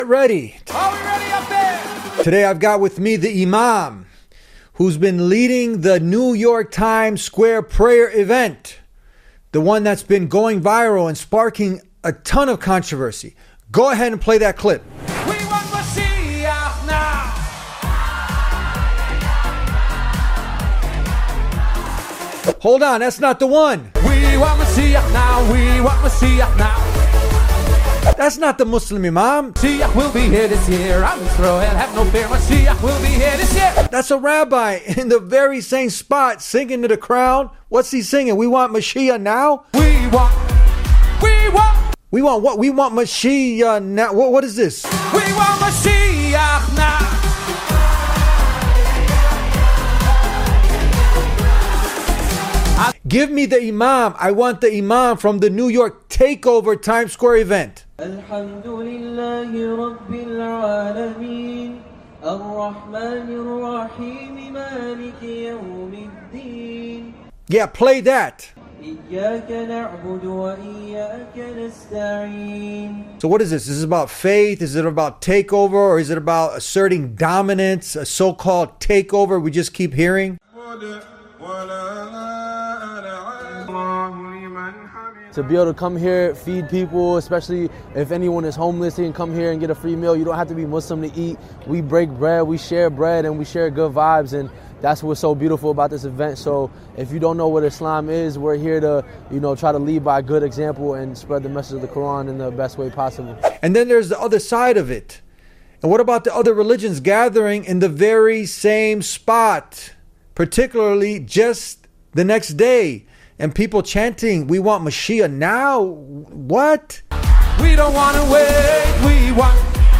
While mainstream media rushed to label a Muslim prayer gathering in Times Square as a “takeover,” the reality on the ground told a very different story — one of faith, unity, and feeding the poor in the heart of New York City. In this eye-opening episode of The Deen Show, an imam devoted to spiritual intelligence and matters of the heart sits down to share the full, unfiltered truth behind the viral Times Square prayer event — now in its fifth consecutive year — and reveals what happened in that exact same spot the very next day that almost nobody talked about.